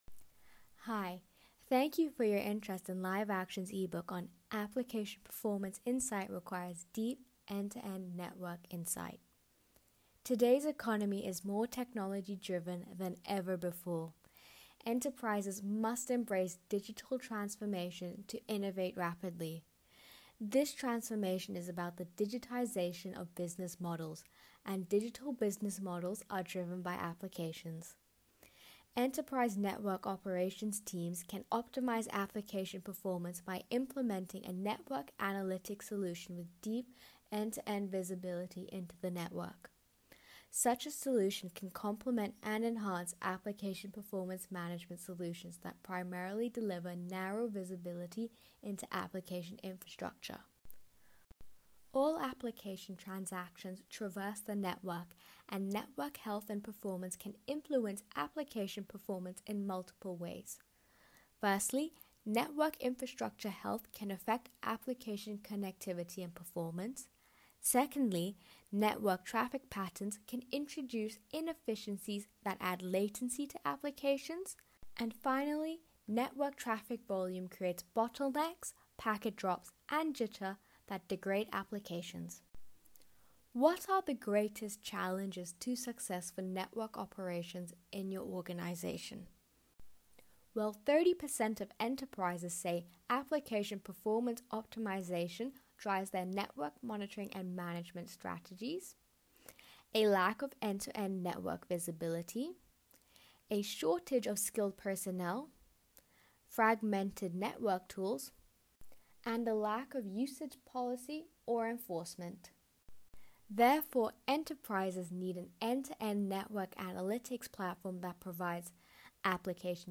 Check out our audiobook, Application Performance Insight Requires Deep, End-to-End Network Insight, for an in-depth look at the current state of business-critical applications and how your NetOps team needs a network analytics platform that provides superior visibility, can assess performance, and facilitate optimization.
app-optimization-audio-book.mp3